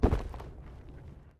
Звуки кувырка